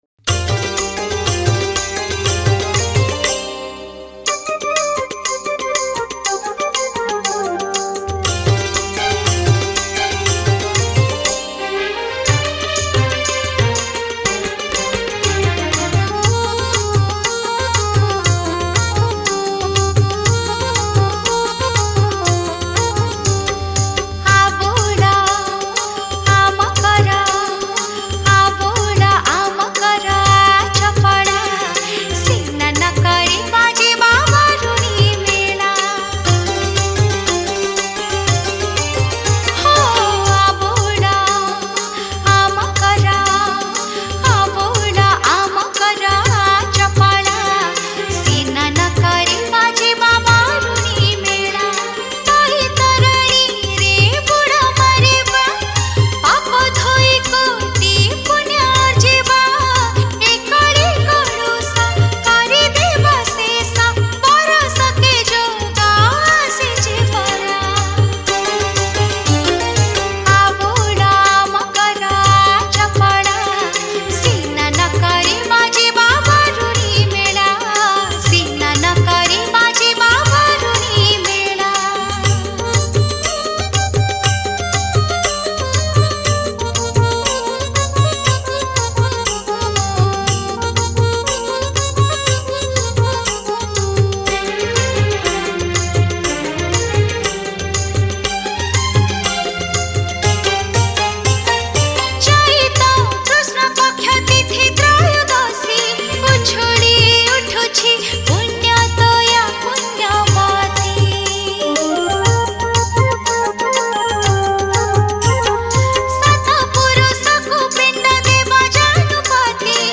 Makara Sankranti Special Bhajan